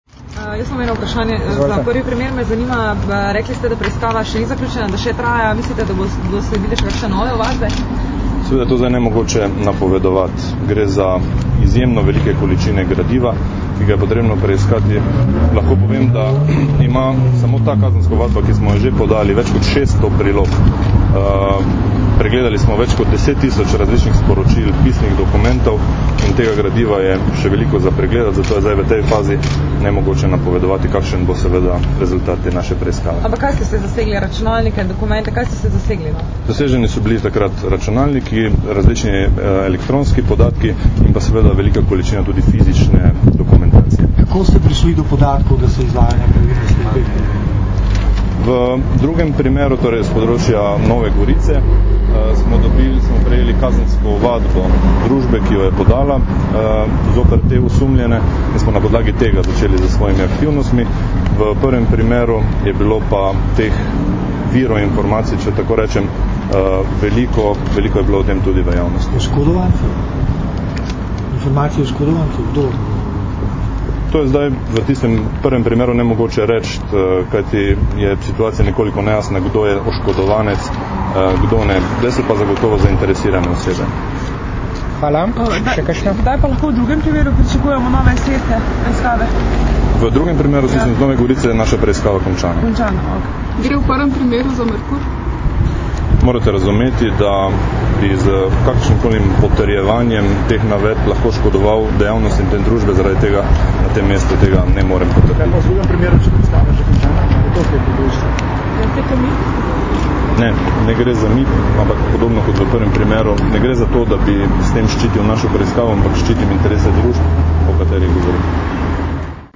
Zvočni posnetek novinarskih vprašanj in odgovorov (mp3)